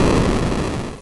Retro Game Weapons Sound Effects – Explosion3 008 – Free Music Download For Creators
Retro_Game_Weapons_Sound_Effects_-_Explosion3__008.mp3